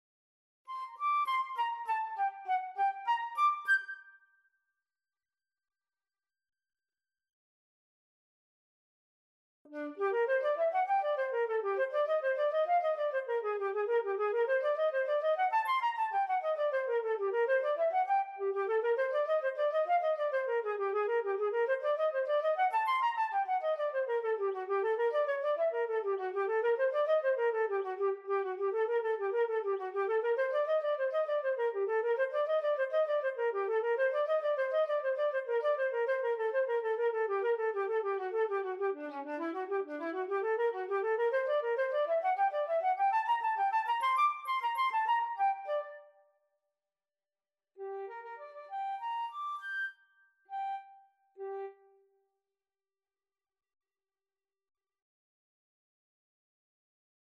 3/8 (View more 3/8 Music)
Db5-G7
G minor (Sounding Pitch) (View more G minor Music for Flute )
Flute  (View more Intermediate Flute Music)
Classical (View more Classical Flute Music)
Flute Orchestral Excerpts